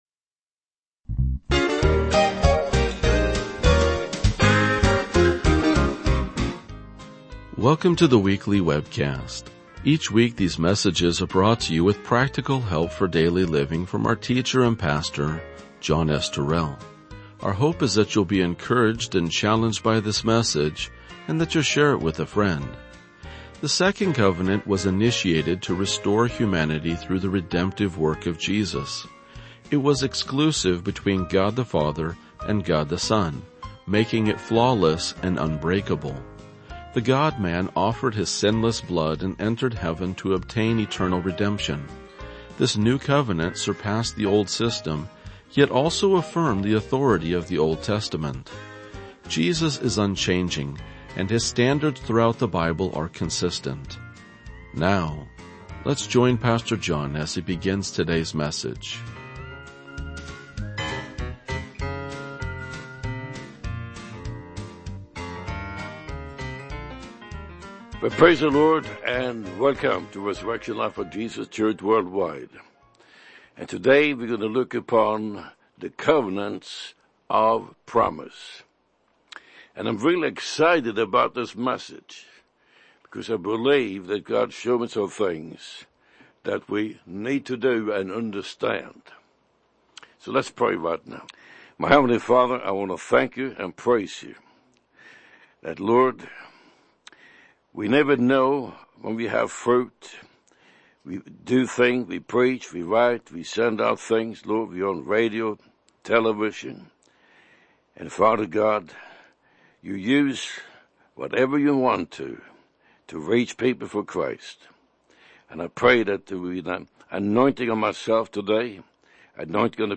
RLJ-2042-Sermon.mp3